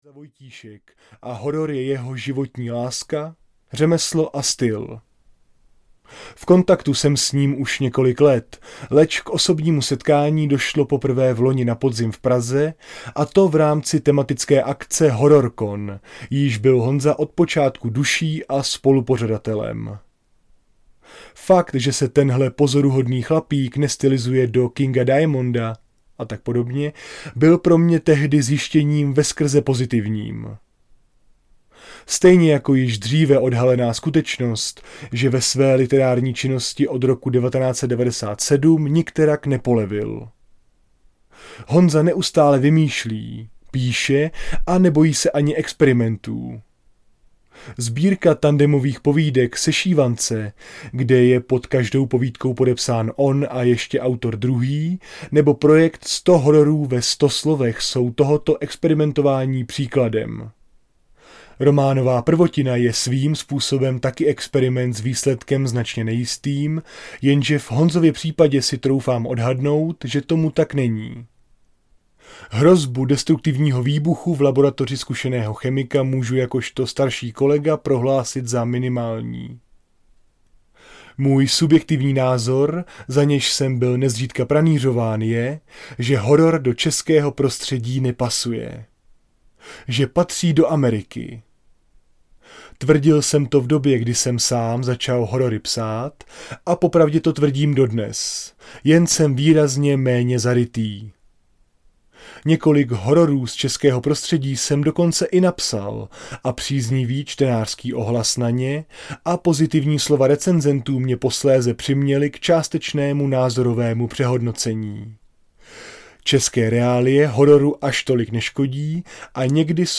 Kazatel audiokniha
Ukázka z knihy